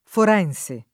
forense [ for $ n S e ] agg. — non forenze